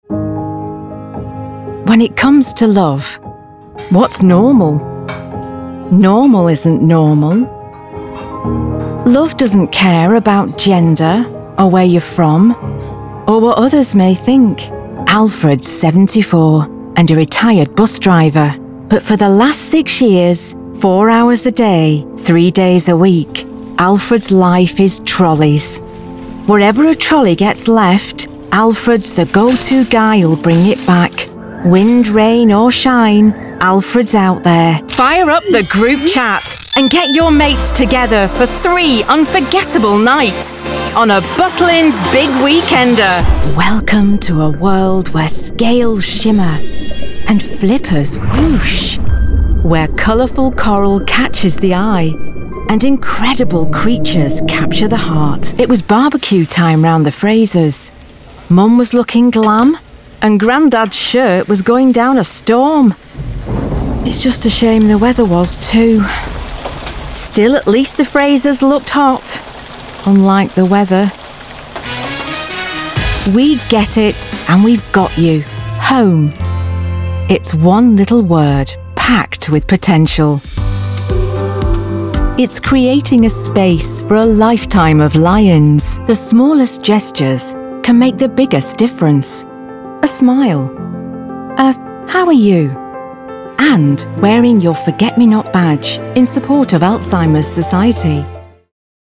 Have a listen to my latest professional Voice Reels which showcase my vocal range and style from empathic to more upbeat and from Lancashire to RP.
British Female Voice Actor located in the North West of England.
I offer a range of voicing styles from a classic British RP to colloquial Lancashire and can be upbeat through to empathic.
Due to demand and quick turnaround times I have invested in a professional recording studio at home including a professional sound isolation vocal booth, which means I can record across geographical time zones and deliver broadcast quality recordings with quick turnaround.
• Neumann TLM 103 Microphone
TV & Radio Commercials are powerful ways to engage with audiences and I am able to deliver a range of voicing styles from upbeat to empathic according to the product or service.